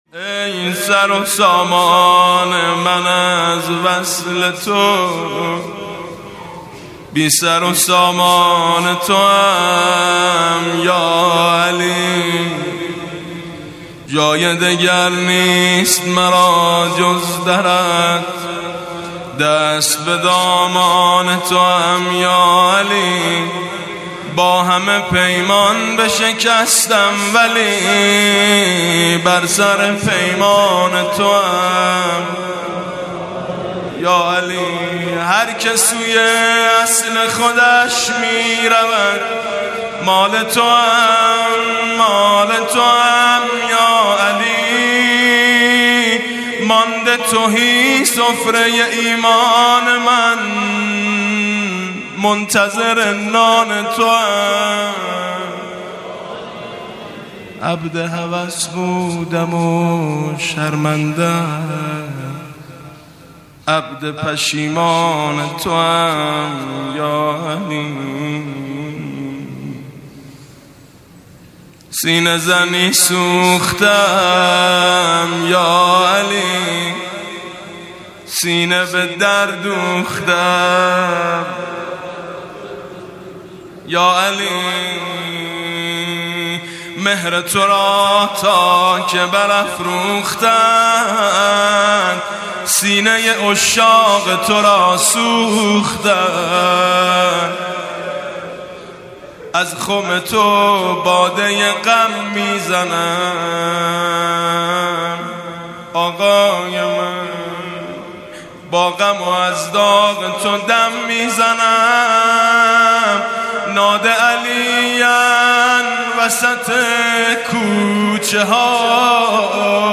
مناسبت : شب بیست و سوم رمضان - شب قدر سوم
مداح : میثم مطیعی قالب : روضه